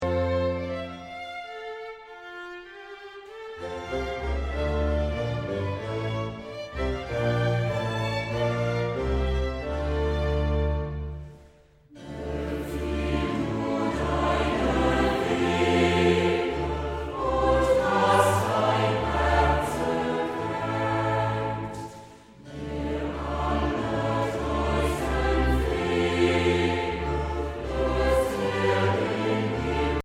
Instrumentalsätze für variable Besetzungen